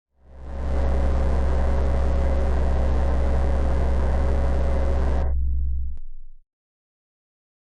SFX
yt_0m44BI94vRw_night_vision_scanner.mp3